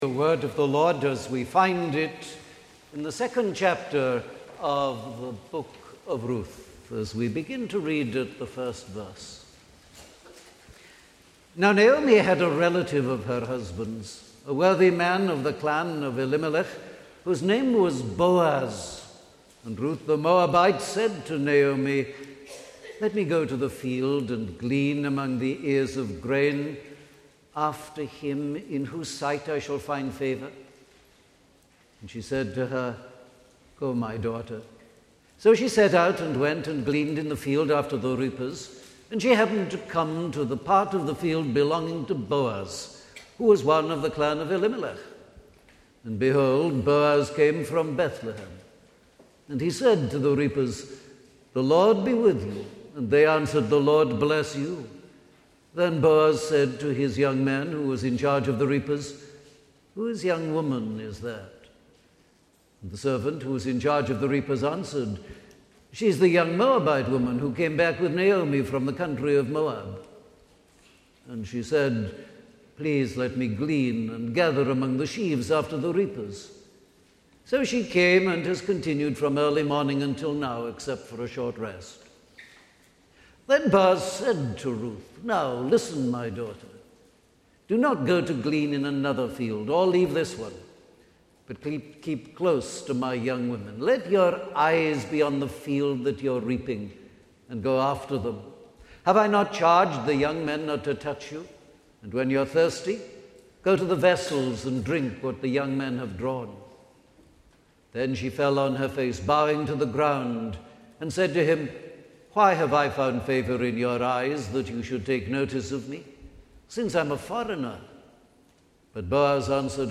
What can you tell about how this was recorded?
Morning Service · Ruth 2:1 - Ruth 2:23